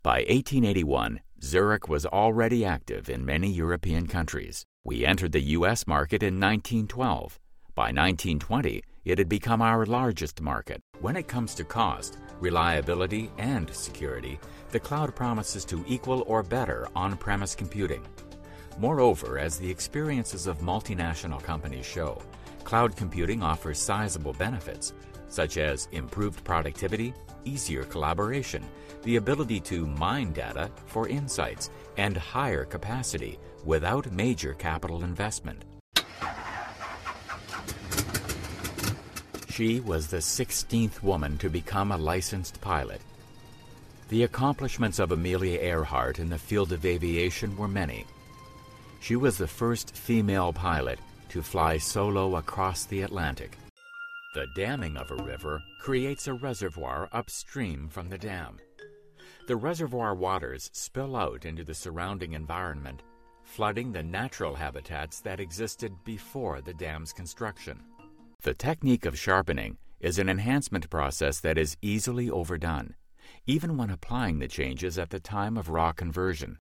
Voice Over; VO; Narrations;
mid-atlantic
Sprechprobe: Sonstiges (Muttersprache):
Warm, Friendly, Authoritative.